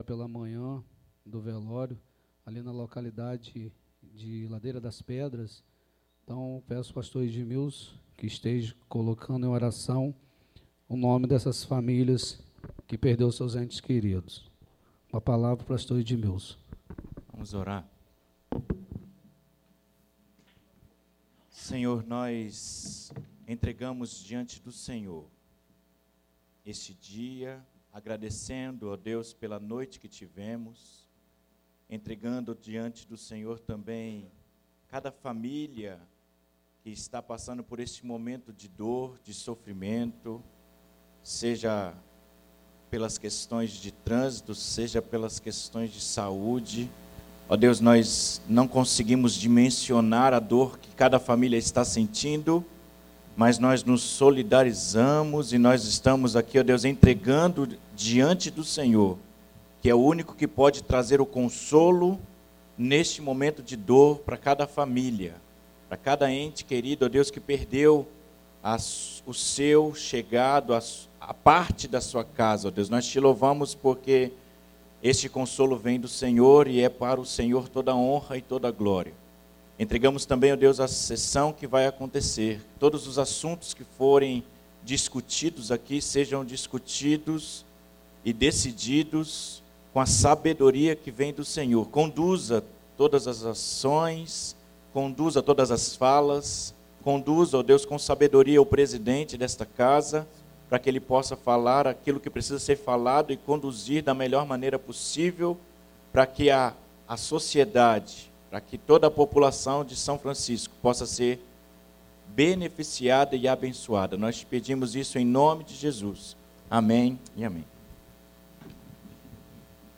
Além desses projetos, a sessão também incluiu a discussão de indicações e requerimentos que foram apresentados e debatidos pelos vereadores.
20-SESSAO-ORDINARIA.mp3